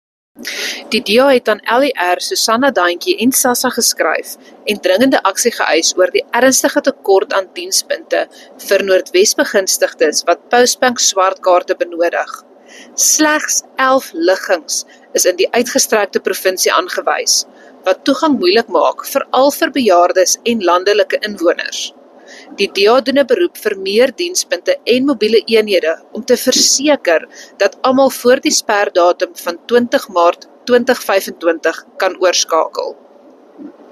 Note to Broadcasters: Please find attached soundbites in English and